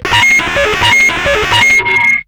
RADIOFX  1-R.wav